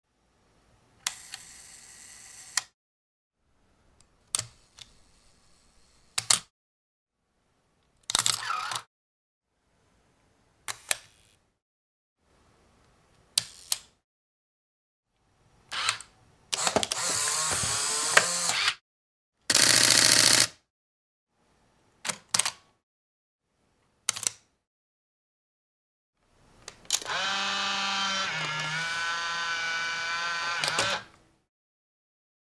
Tổng hợp tiếng màn trập máy ảnh Film
Thể loại: Tiếng đồ công nghệ
Description: Tất cả các âm thanh màn trập máy ảnh Film, Noisy Buttons - a camera shutter sound film, lần lượt là Yashica-D, Nikon FM2/T, Nikon FG, Leica M6, Rollei 35se, Polaroid SX-70, Canon EOS-1D X Mark II, Nikon F100, Sony A7 II, Fujifilm Instax 210...
tong-hop-tieng-man-trap-may-anh-film-www_tiengdong_com.mp3